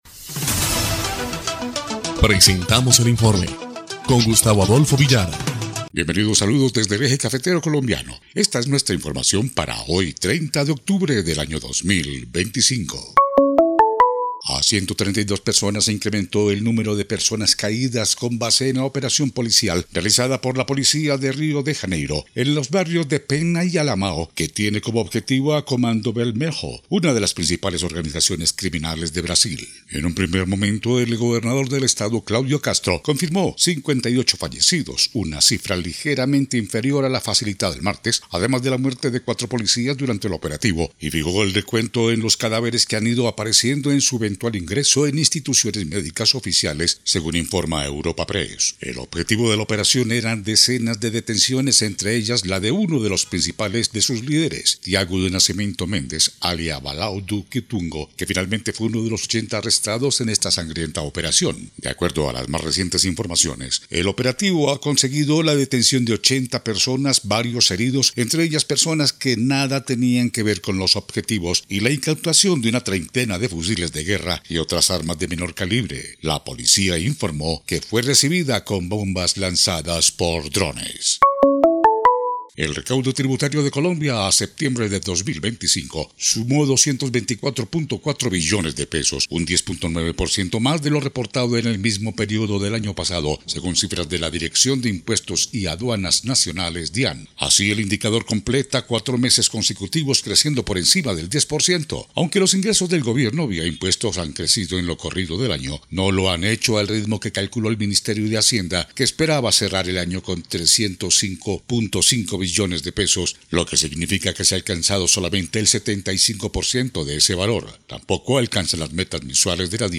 EL INFORME 1° Clip de Noticias del 30 de octubre de 2025